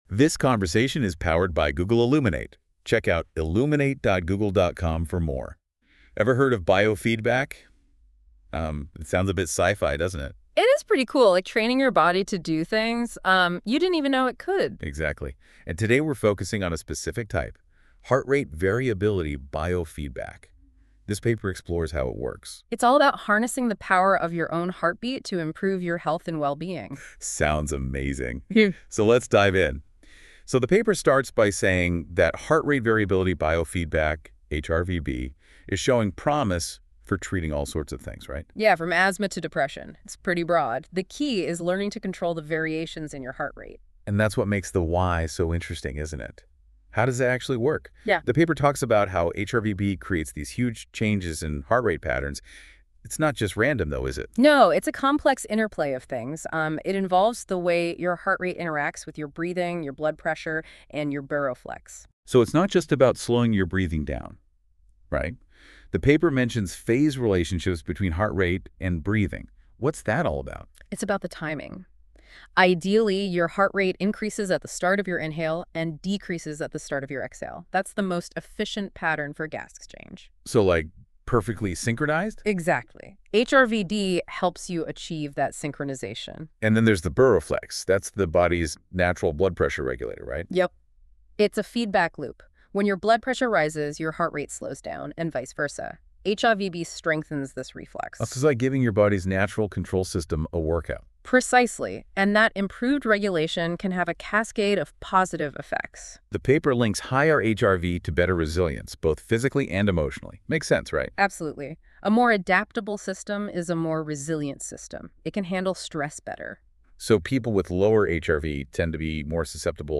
Google Illuminate discussion of Lehrer and Gevirtz's classic " Heart Rate Variability Biofeedback: How and Why Does it Work? ," published in the 2014 Frontiers in Psychology .